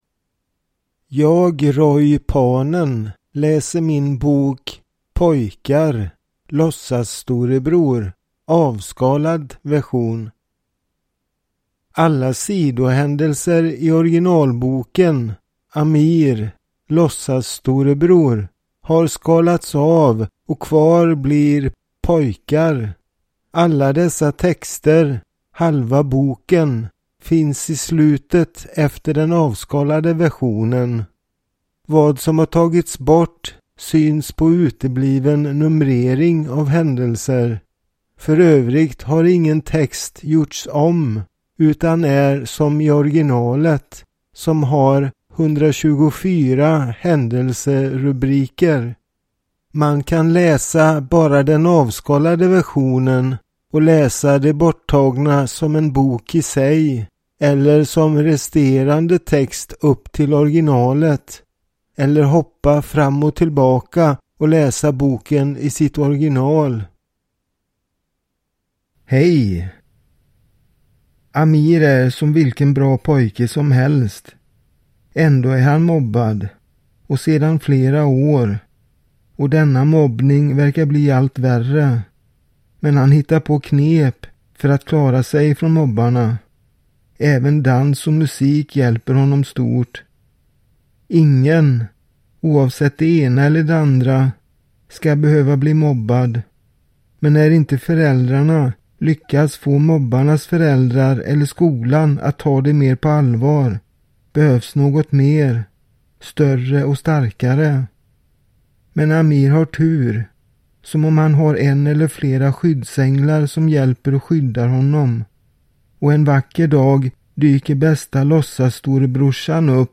POJKAR Låtsasstorebror (avskalad) (ljudbok) av Roy Panen